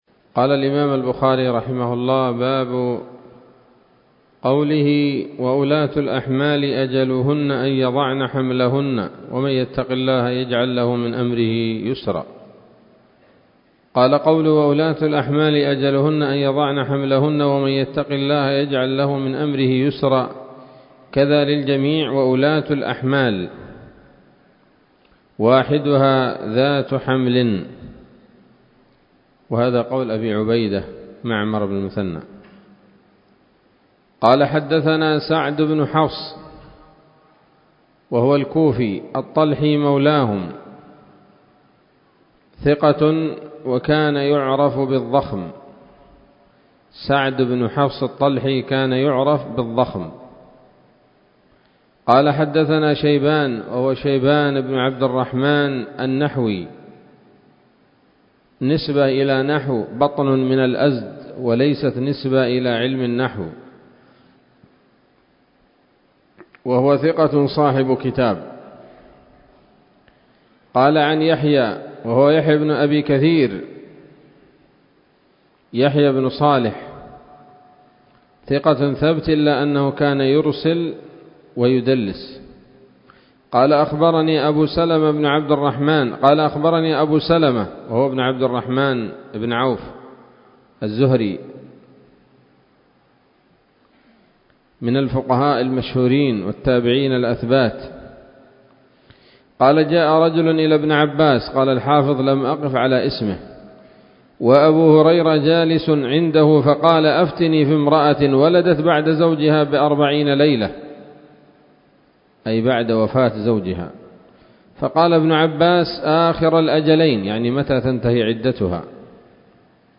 الدرس السابع والستون بعد المائتين من كتاب التفسير من صحيح الإمام البخاري